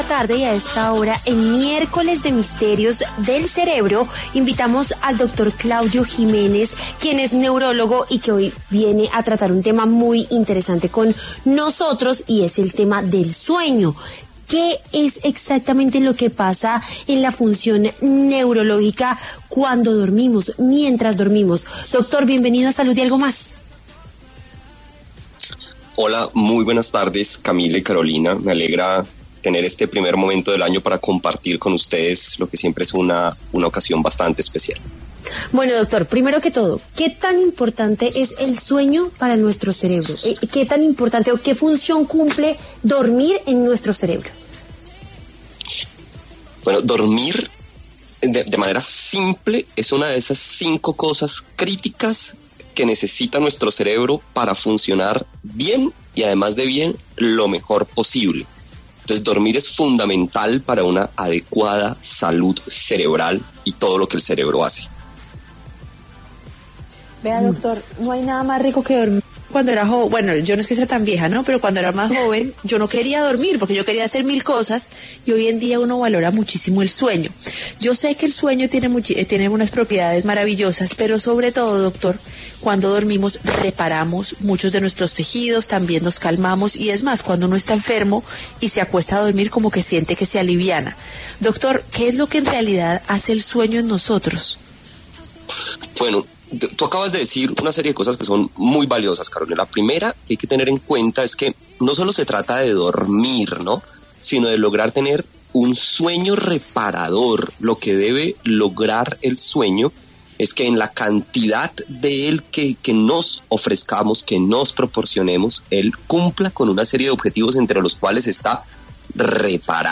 pasó por los micrófonos de Salud y Algo Más para hablar sobre los ‘Misterios del Cerebro’ y lo que ocurre con él mientras dormimos.